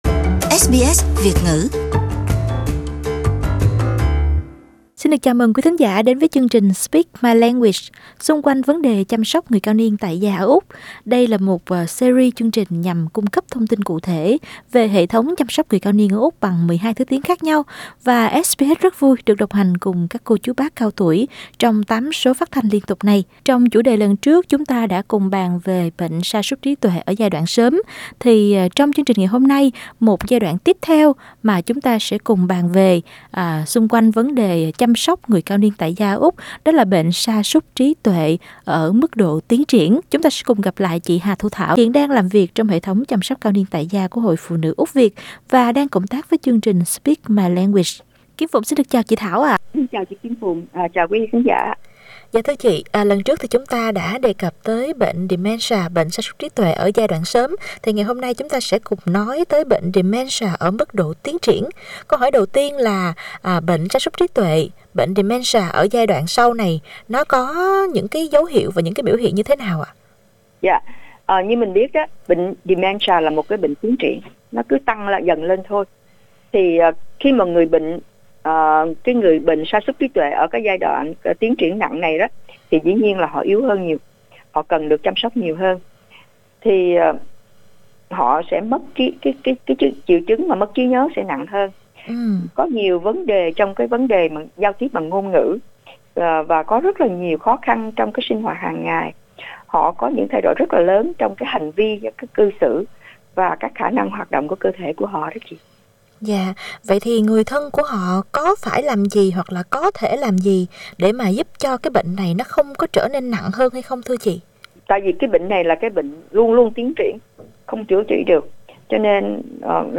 Chị đã có cuộc trò chuyện với SBS xung quanh chủ đề này: 1.